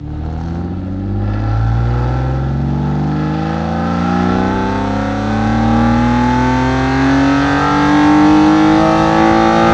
rr3-assets/files/.depot/audio/Vehicles/i6_02/i6_02_accel.wav
i6_02_accel.wav